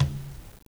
Tumba-HitN_v1_rr1_Sum.wav